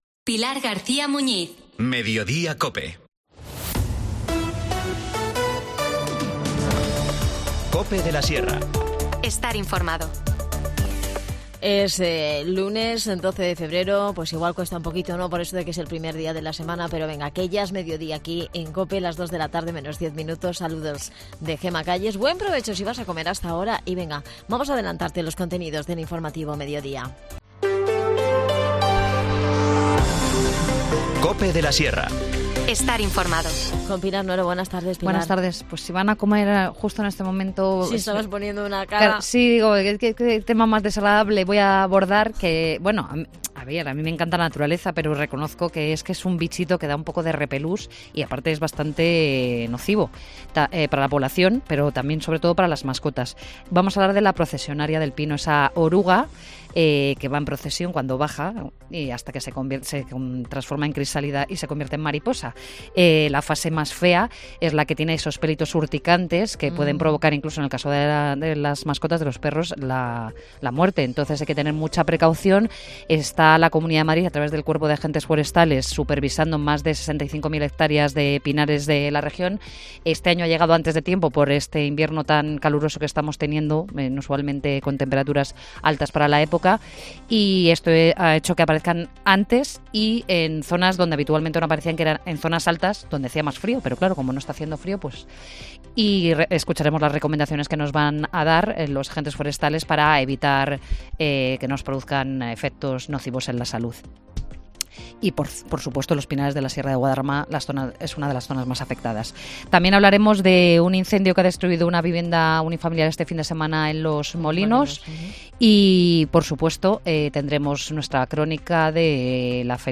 Ignacio Menéndez, concejal de Comercio, nos explica cómo puedes participar en esta campaña que estará activa hasta el próximo 17 de febrero.